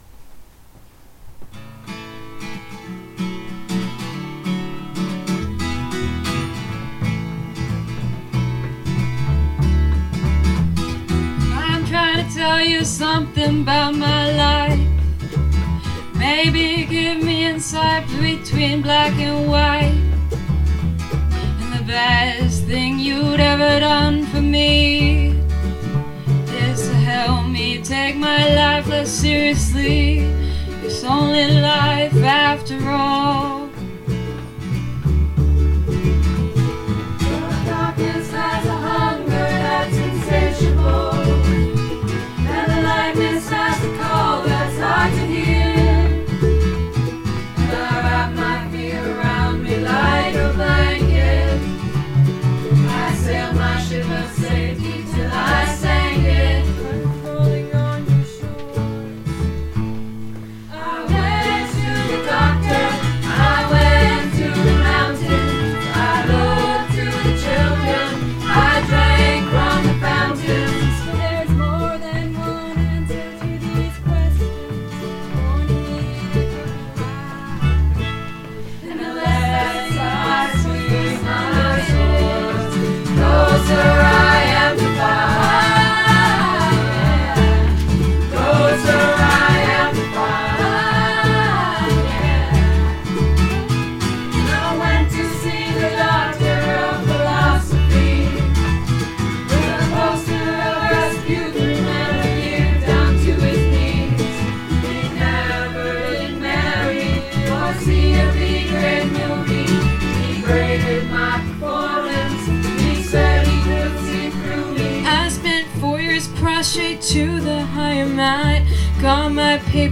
the students
Madrigals